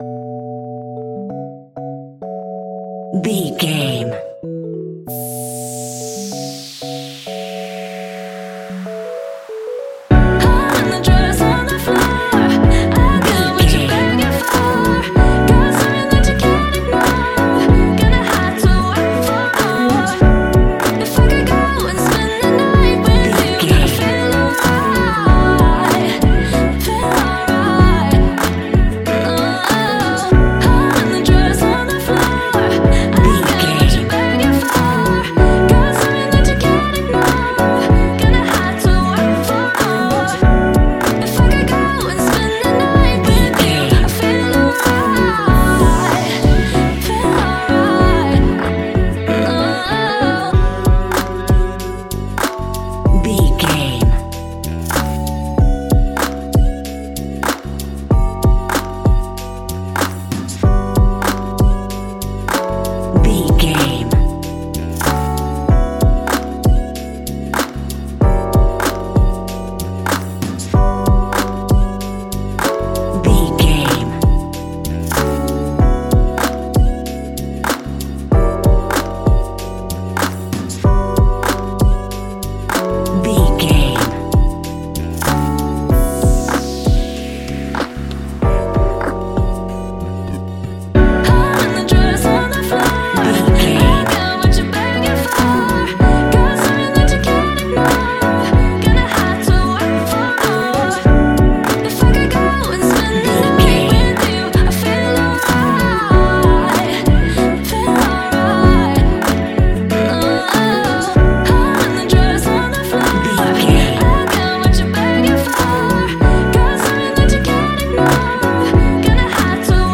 Ionian/Major
F♯
laid back
Lounge
sparse
new age
chilled electronica
ambient
atmospheric
morphing
instrumentals